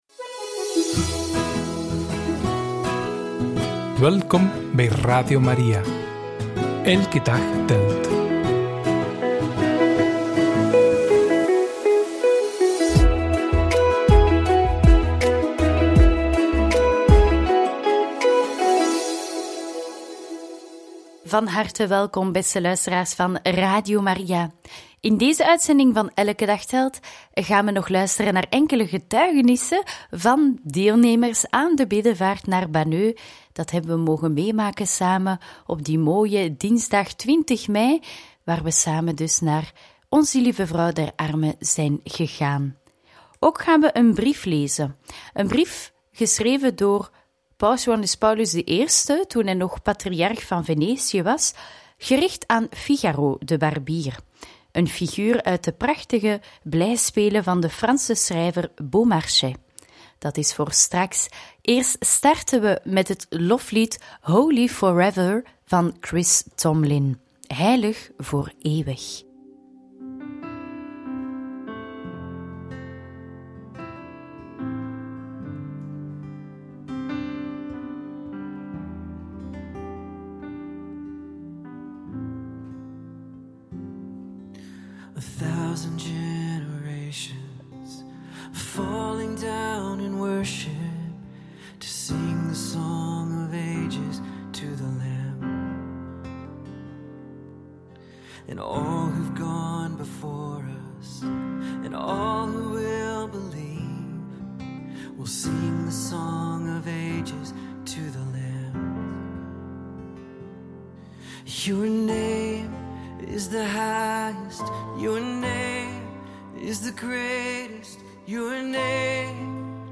In gesprek met onze luisteraars in Banneux! – Brief aan Figaro – Radio Maria